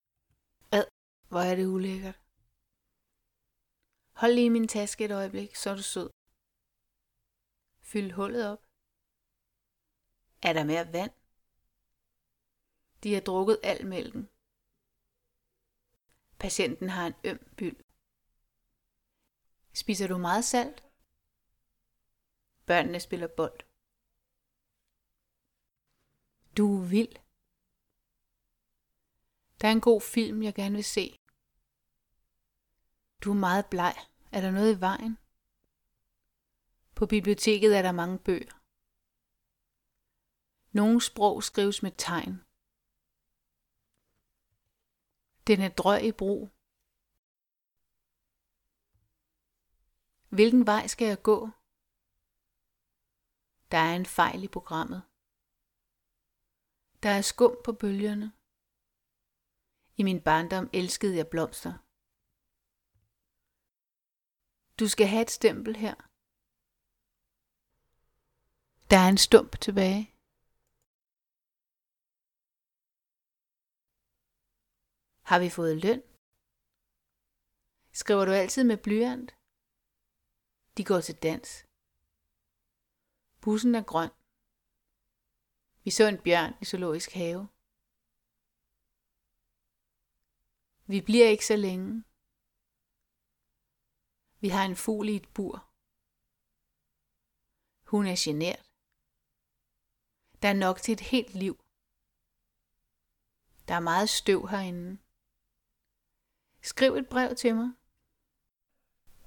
Stød på konsonant
stød-på-kons.mp3